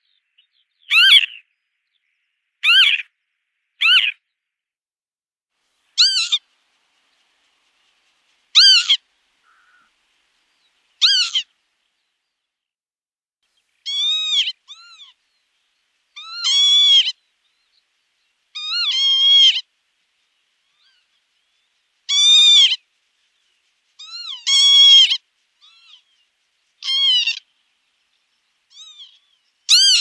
Vanneau huppé - Mes zoazos
Il fréquente les prairies humides et les terres agricoles, où il nidifie au sol. Son vol onduleux et ses cris roulés sont caractéristiques.
vanneau-huppe.mp3